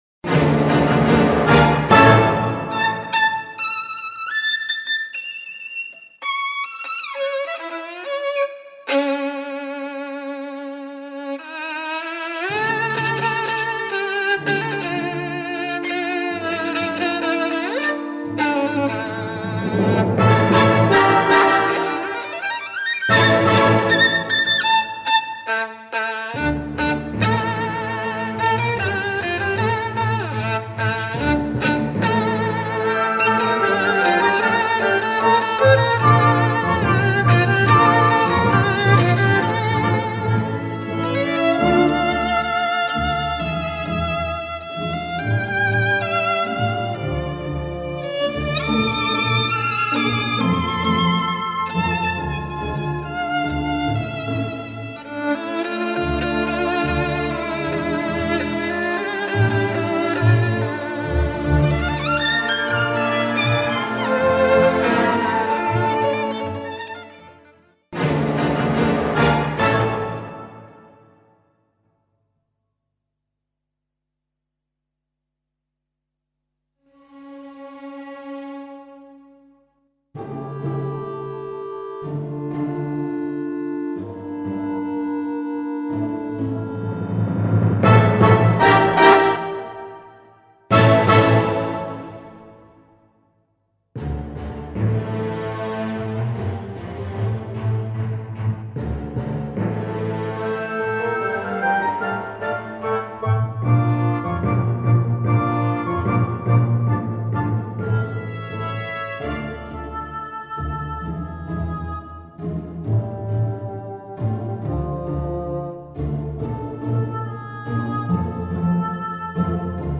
Web-Symphony-Espagnole-Violin.mp3